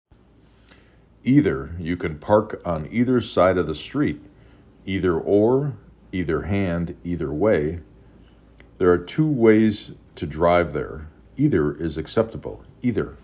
6 Letters, 2 Syllable
E D er
I D er